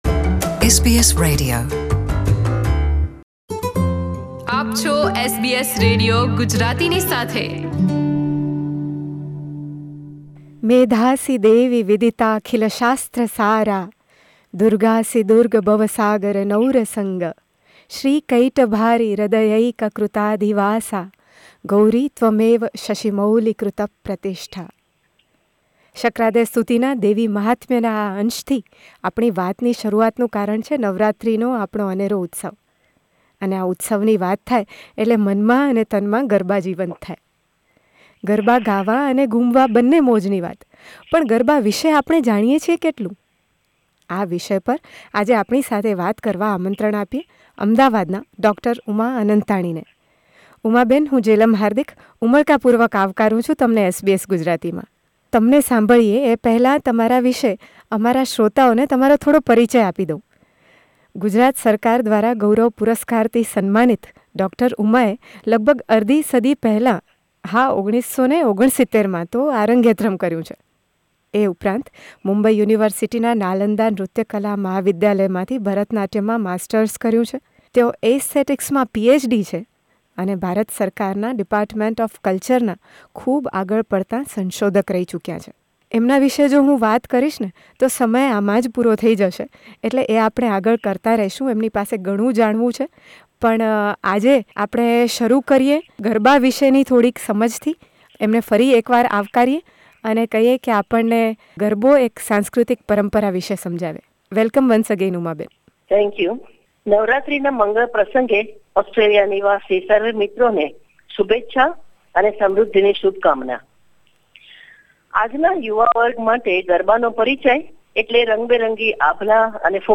From her various talks with SBS Gujarati on this subject, this first conversation is on Garba as our cultural heritage.